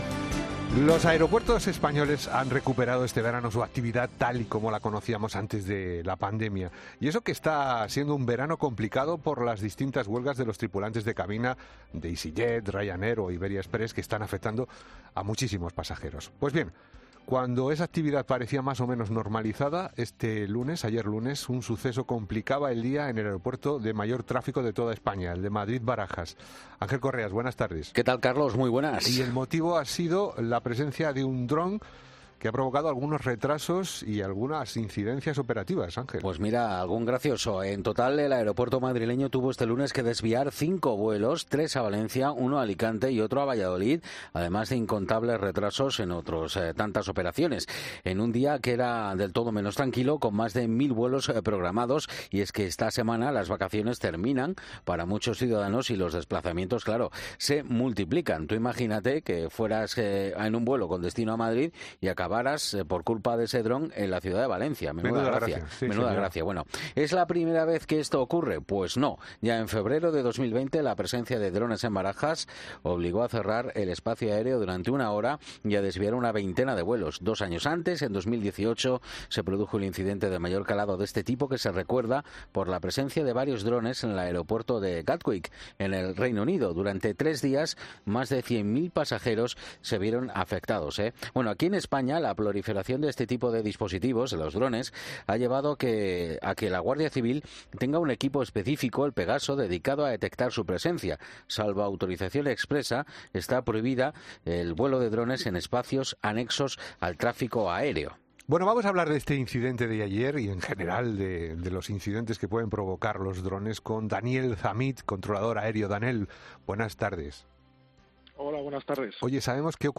Varios expertos en vuelos aéreos y en drones explican cómo es el funcionamiento de la normativa